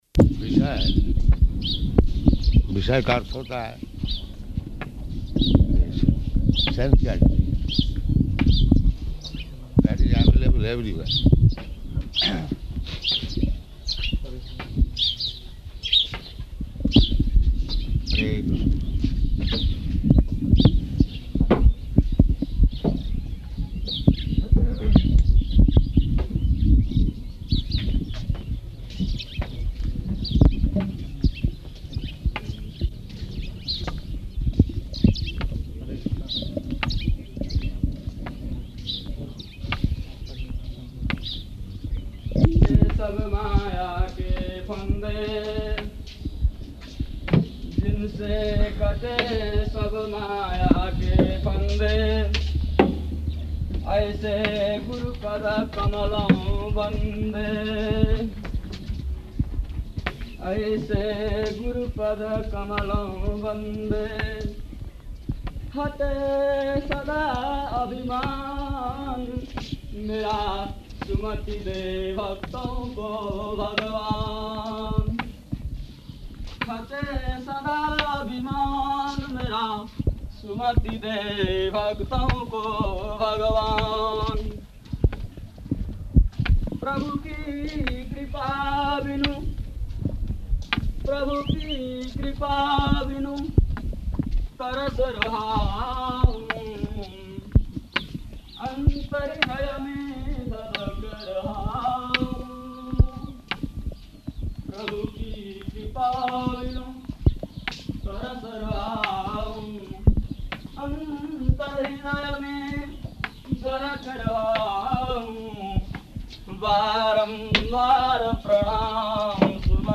Morning Walk --:-- --:-- Type: Walk Dated: January 22nd 1976 Location: Māyāpur Audio file: 760122MW.MAY.mp3 Prabhupāda: Viṣaya...